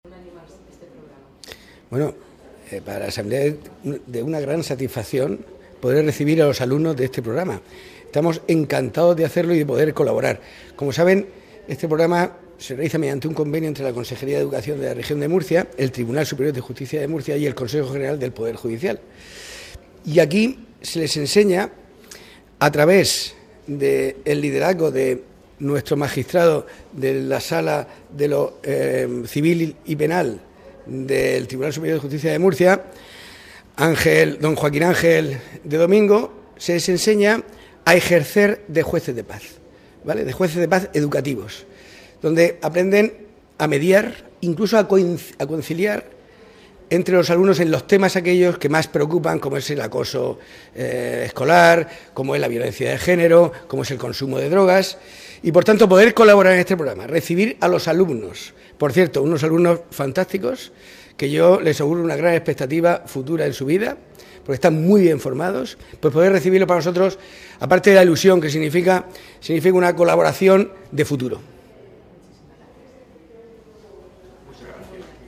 • Declaraciones del vicepresidente primero de la Asamblea Regional, Miguel Ángel Miralles